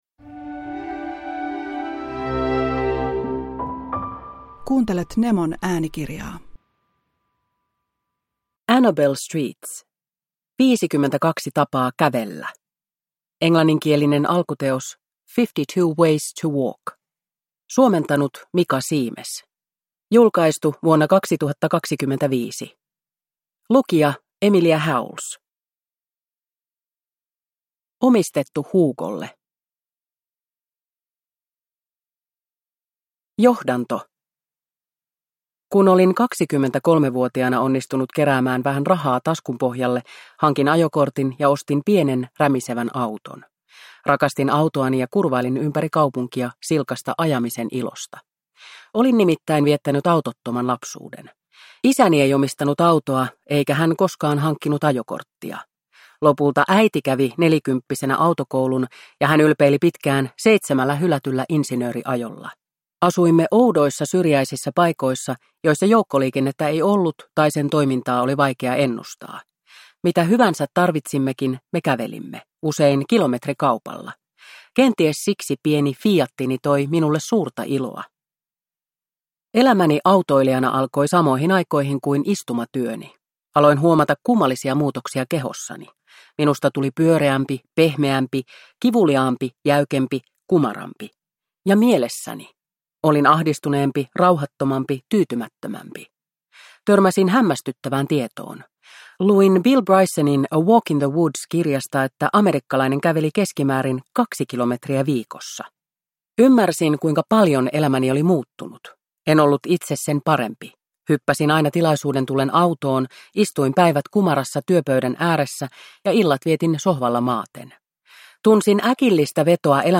52 tapaa kävellä – Ljudbok